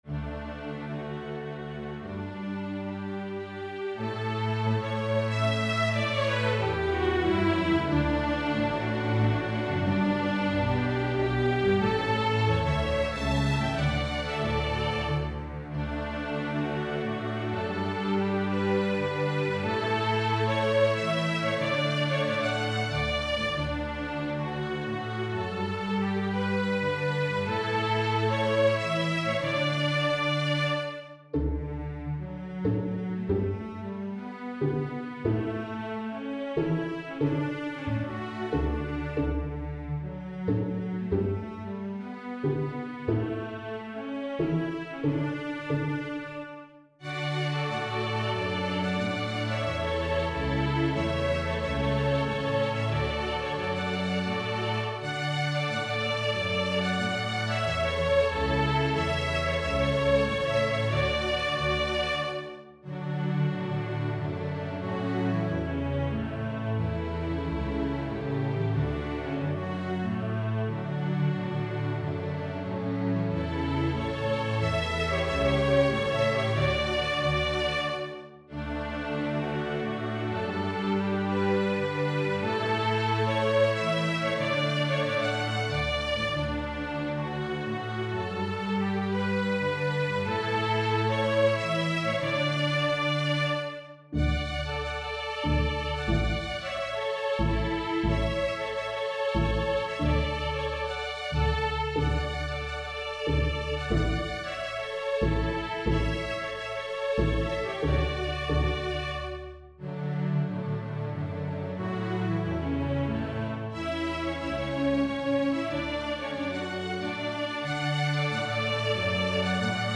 String Orchestra